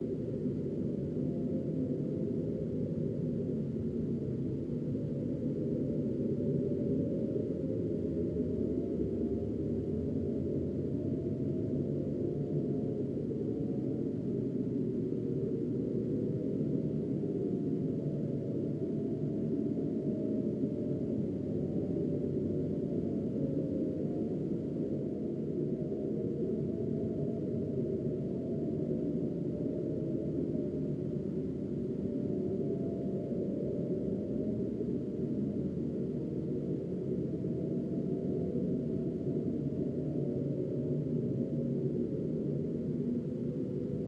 wind-space-platform.ogg